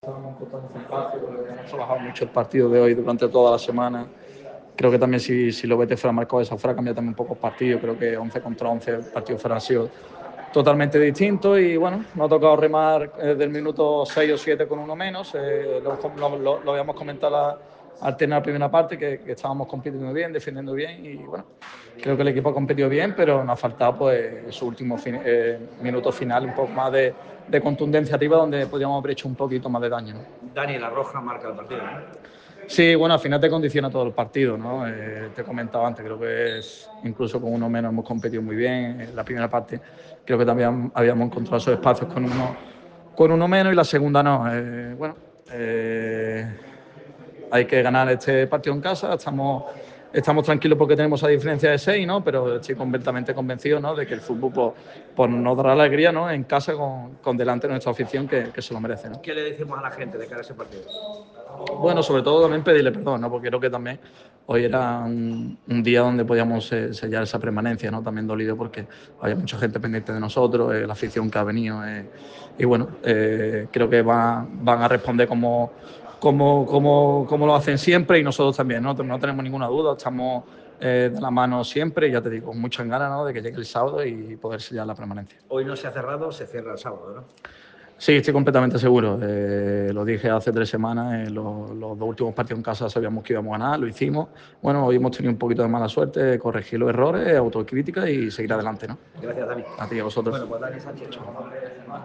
ha comparecido ante los medios en zona mixta